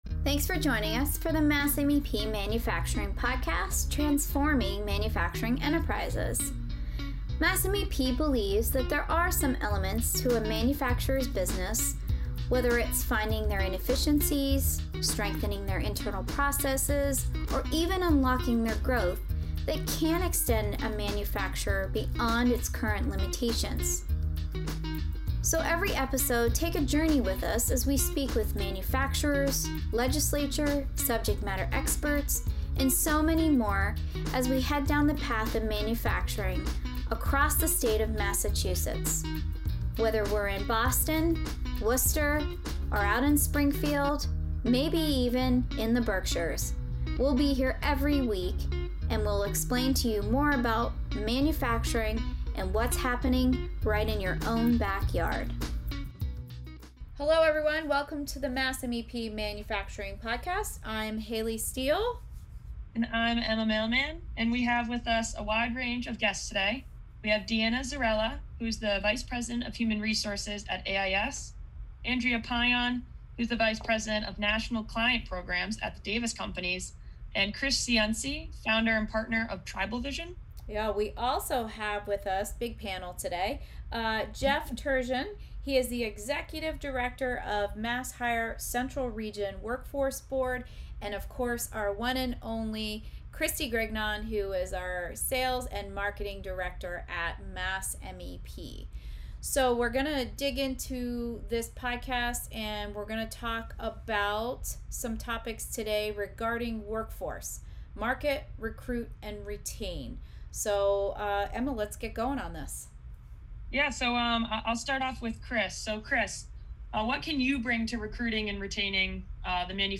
We sit down with a panel to discuss the what manufacturers can do to market, recruit and retain their workforce in our current climate.